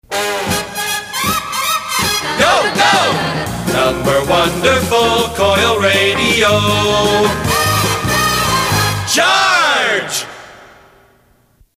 NOTE: These jingle samples are from my private collection.